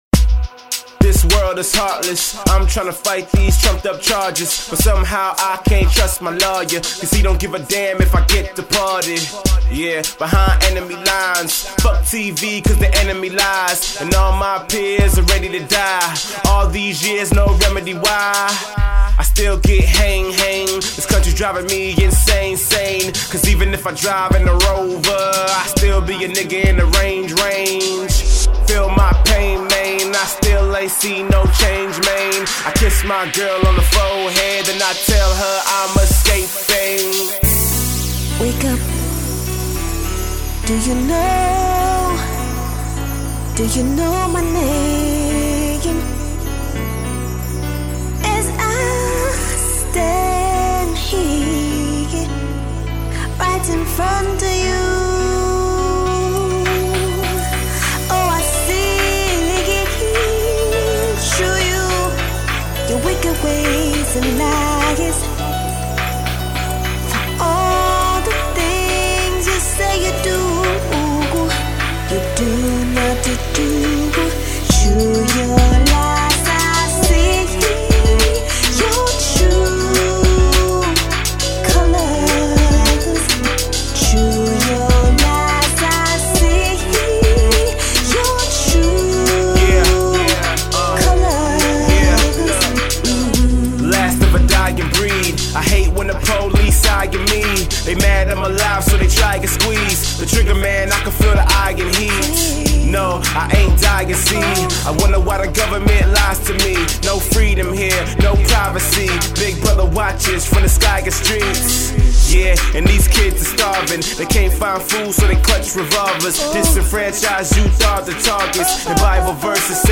political and socially conscious lyrics and songs